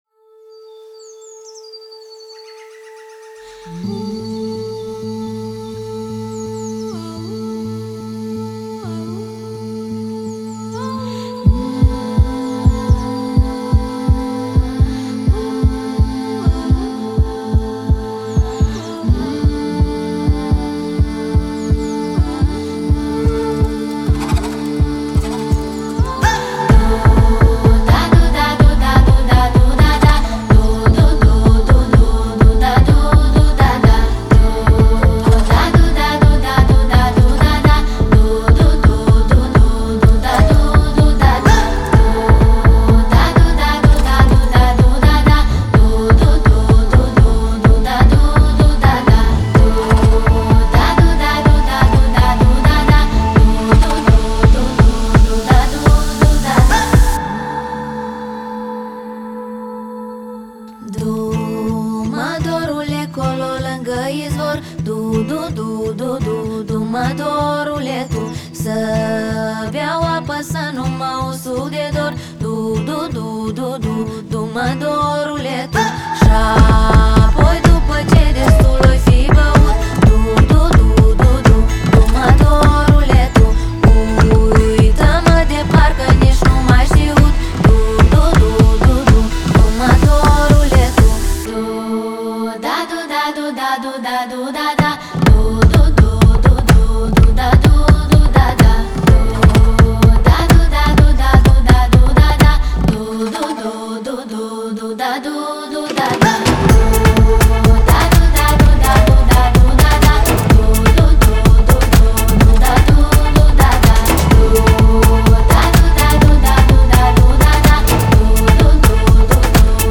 • Жанр: House, Dance